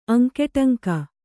♪ aŋkeṭaŋka